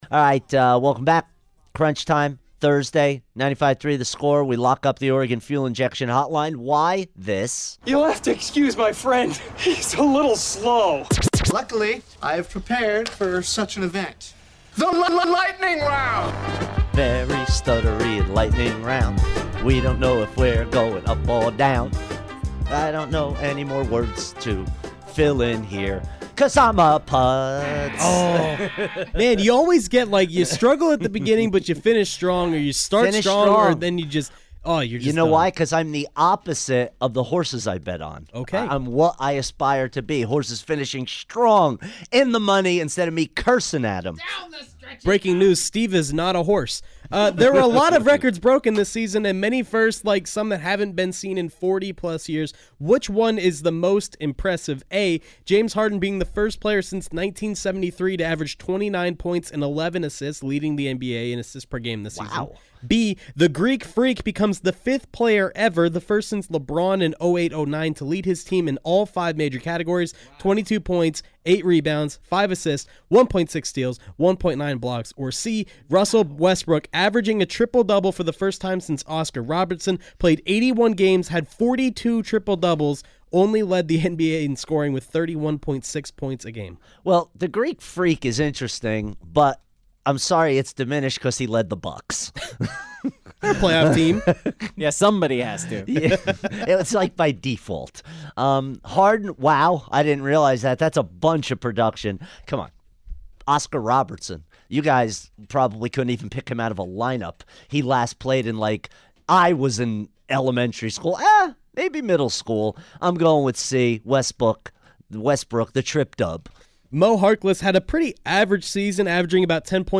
fires through the biggest stories rapid-fire style